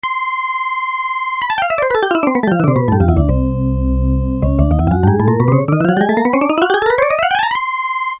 The fact that the black keys on this keyboard are no taller than the white ones, incidentally, also makes one new trick possible.
gliss.mp3